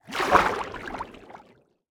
Minecraft Version Minecraft Version latest Latest Release | Latest Snapshot latest / assets / minecraft / sounds / ambient / underwater / enter1.ogg Compare With Compare With Latest Release | Latest Snapshot